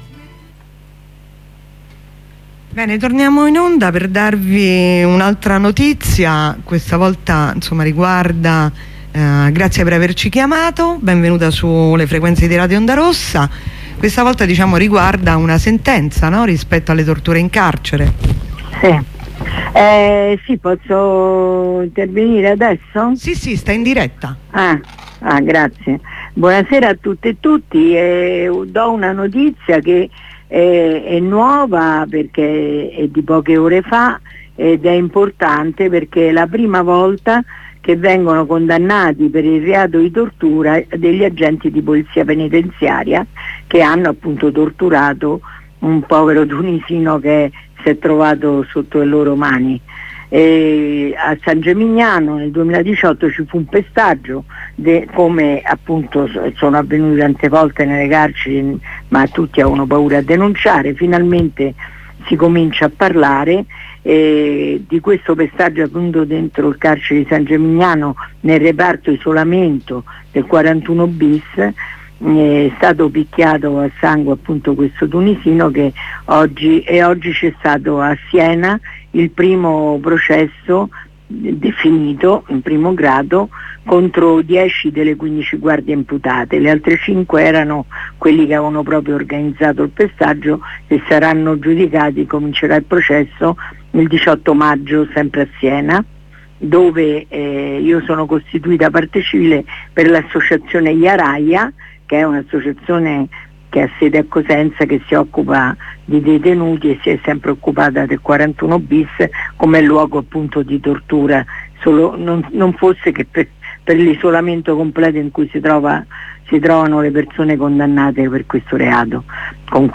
h 15:20 collegamento dalla nuova occupazione di bologna